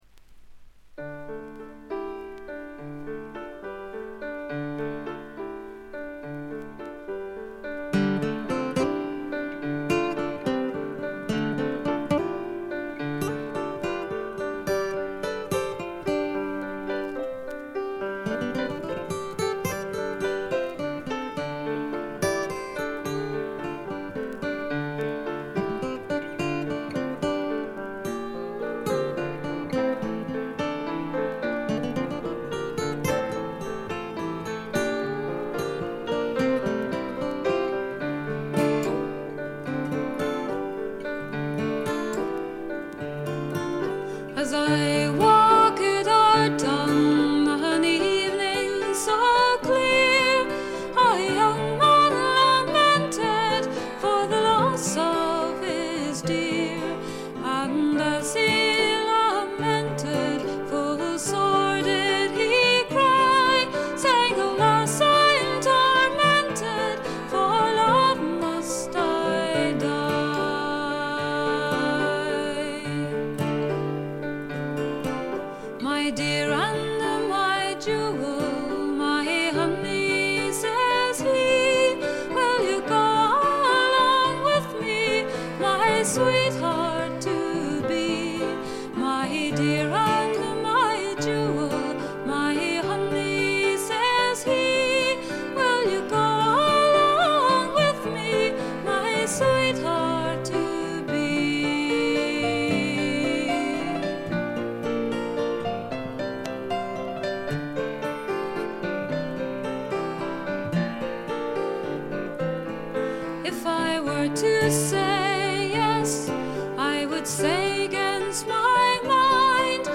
軽微なチリプチが少々出る程度。
アイルランドの女性シンガー・ソングライターでトラッドと自作が半々という構成。
天性のとても美しい声の持ち主であるとともに、歌唱力がまた素晴らしいので神々しいまでの世界を構築しています。
試聴曲は現品からの取り込み音源です。
Acoustic Guitar
Bagpipes [Uilleann Pipes]
Vocals, Piano